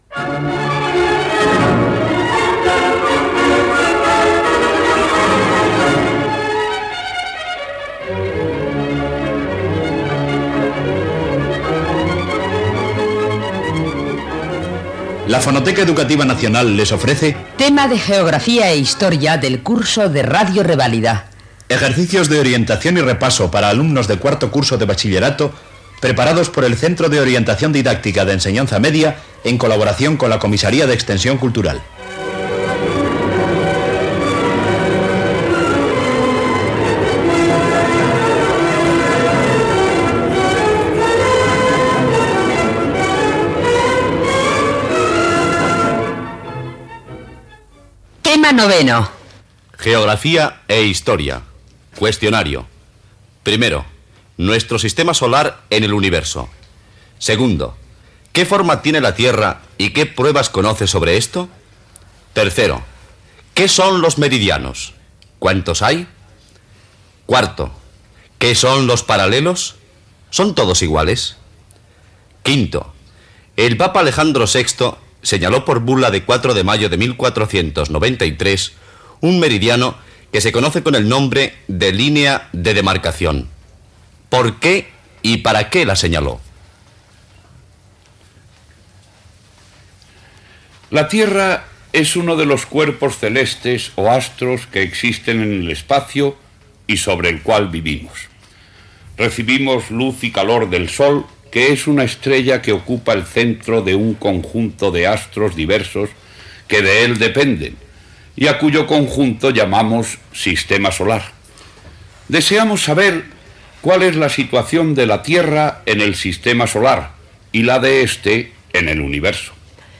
Careta del programa, lliçó del tema 9 de Geografia i Història per a l'alumnat de batxillerat